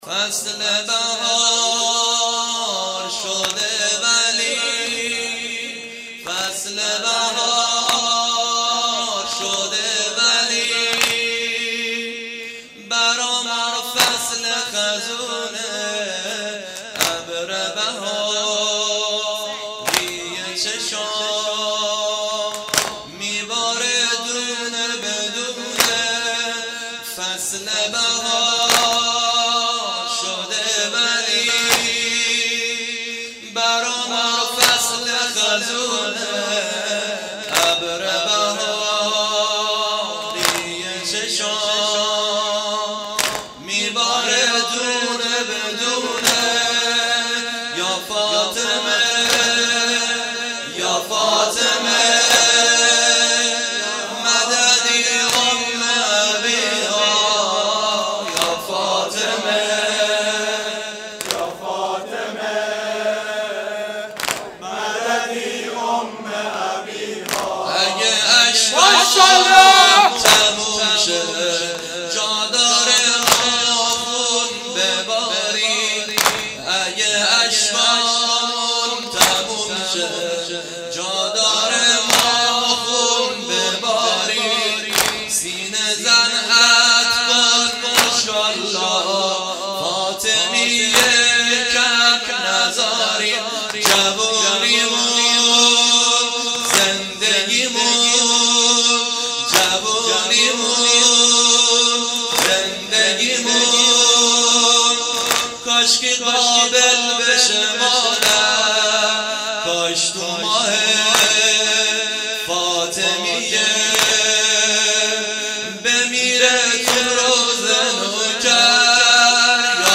ایام فاطیمه دوم«شهادت  حضرت فاطمه الزهرا(س) (شب پنجم)
بخش سوم....سنگین...فصل بهار شده...